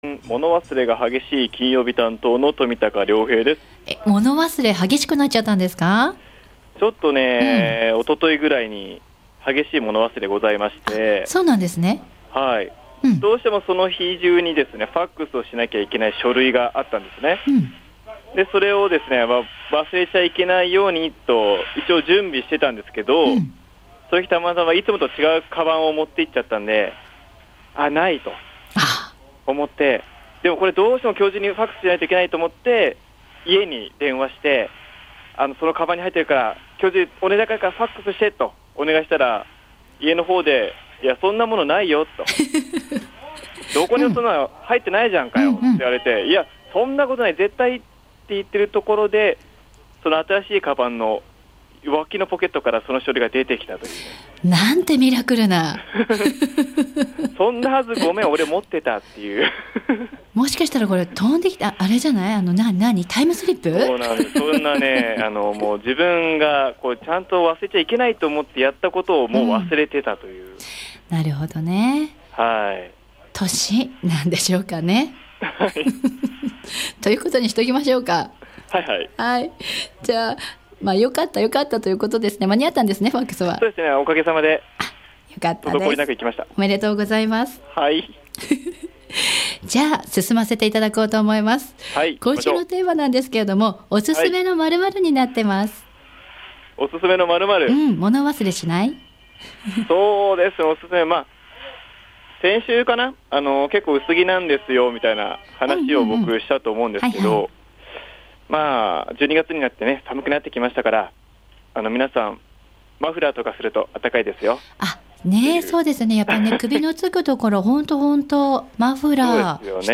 午後のカフェテラス 街角レポート
お伺いしたのはつつじヶ丘商店会歳末福引抽選会の会場♪ 毎年お馴染みのこちらのイベントは、１１月２０～１２月８日までの間つつじヶ丘商店会加盟店で お客様に配布している抽選券で参加が出来ます！！